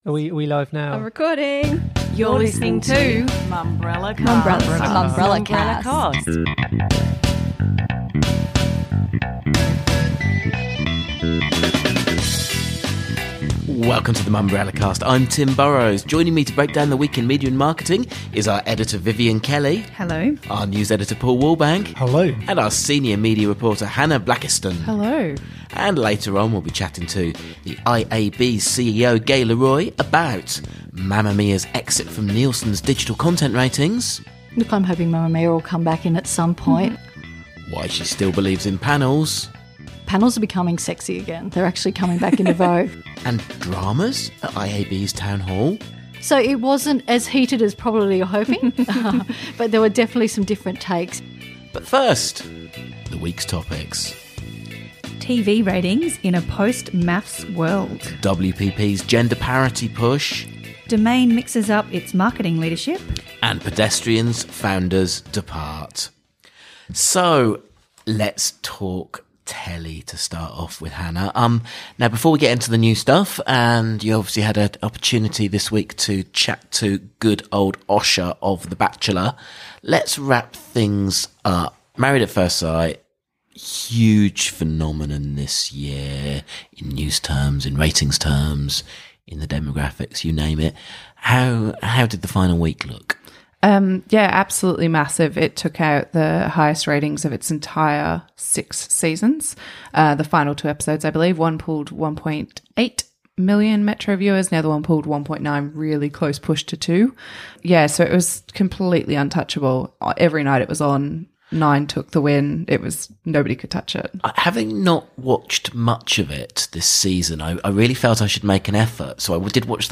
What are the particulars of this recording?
joining us in studio